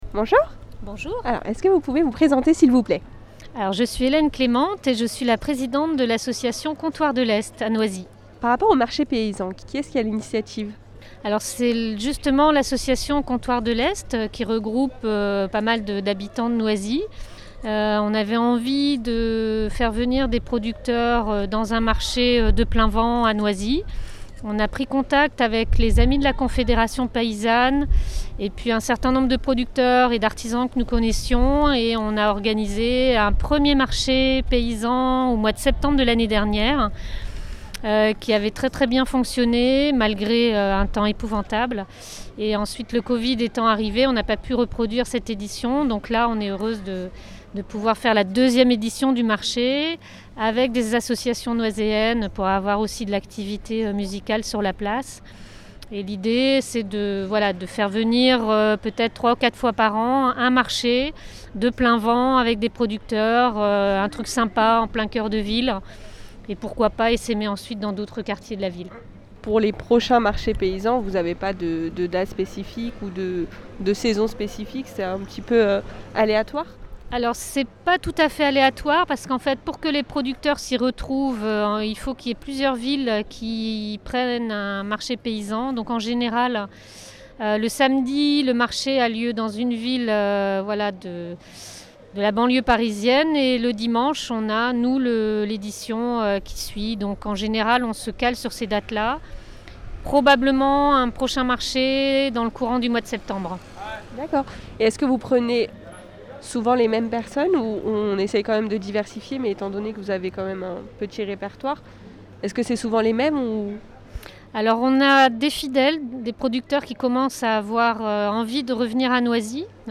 Interview
AU MARCHE PAYSAN